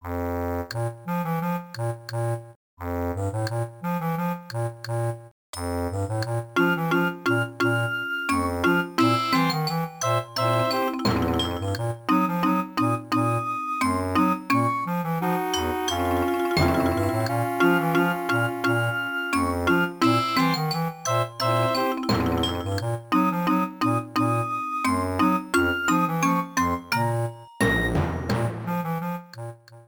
Ripped from game data, then trimmed in Audacity
Fair use music sample